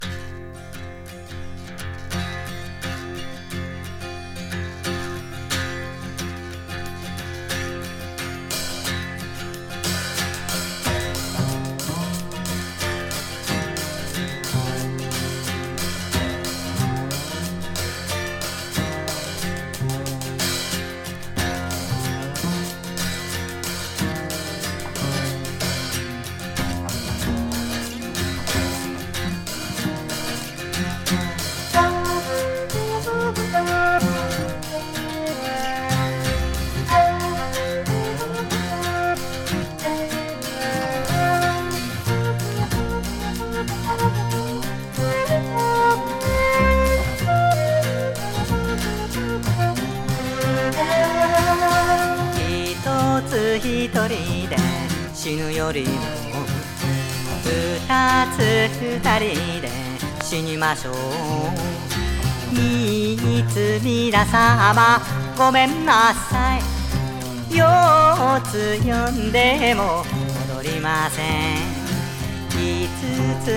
シタールや尺八、ギター等をバックに